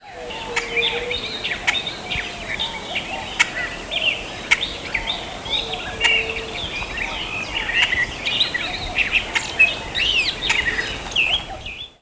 Great-tailed Grackle diurnal flight call
"Pwuk" calls from bird in flight.